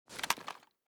mossberg_reload_end.ogg.bak